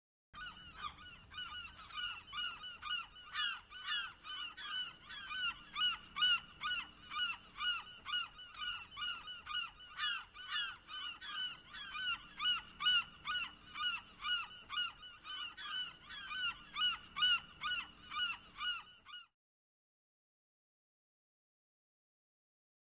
Звуки чайки
Звук чаек, кричащих в полете